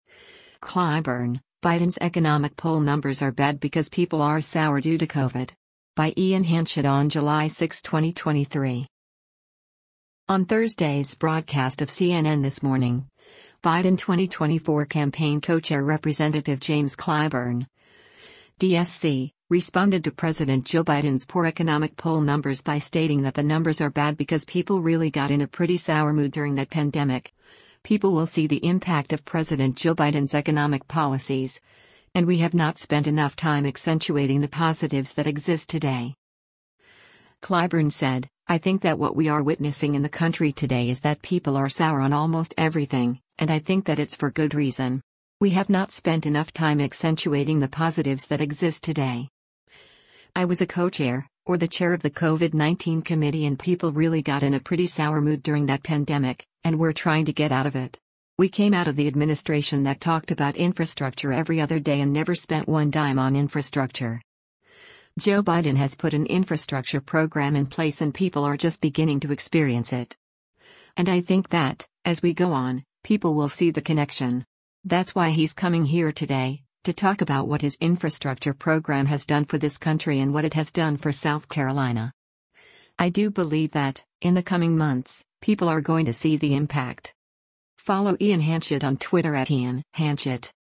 On Thursday’s broadcast of “CNN This Morning,” Biden 2024 Campaign Co-Chair Rep. James Clyburn (D-SC) responded to President Joe Biden’s poor economic poll numbers by stating that the numbers are bad because “people really got in a pretty sour mood during that pandemic,” people will see the impact of President Joe Biden’s economic policies, and “We have not spent enough time accentuating the positives that exist today.”